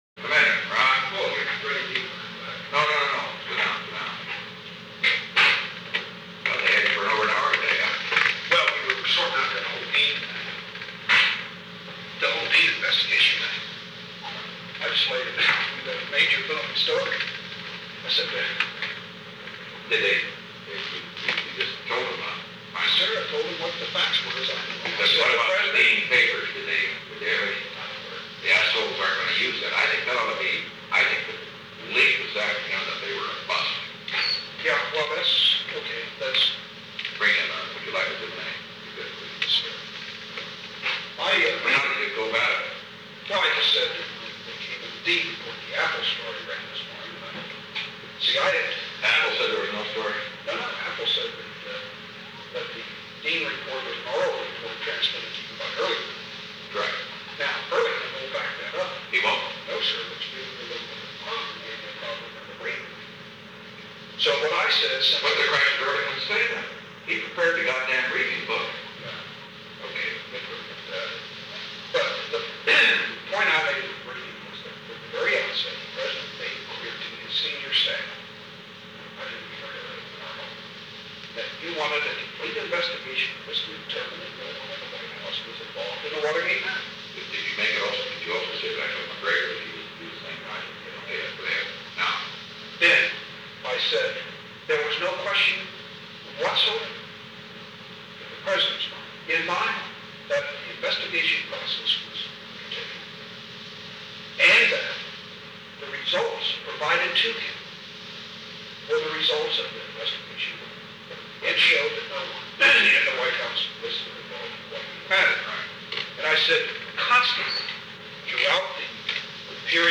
Secret White House Tapes | Richard M. Nixon Presidency